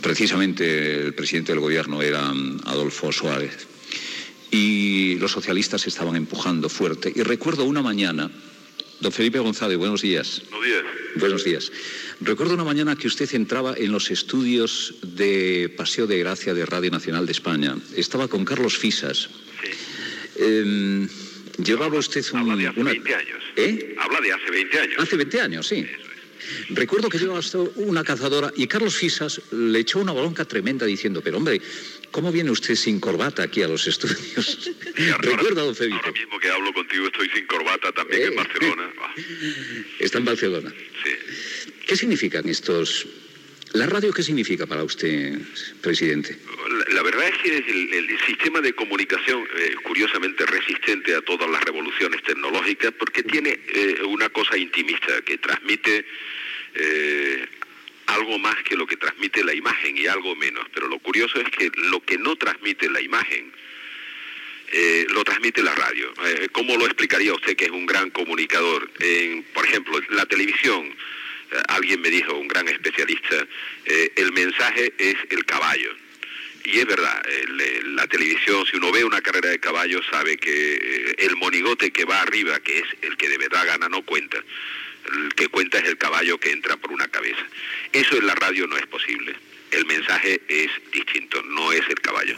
Intervenció telefònica de l'ex president del Govern espanyol Felipe González en el 25è aniversari del programa
Info-entreteniment